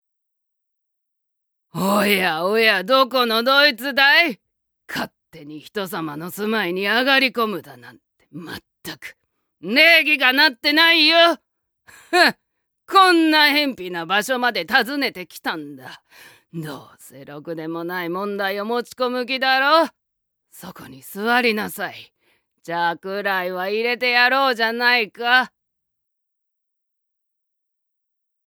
◆頑固な老婆◆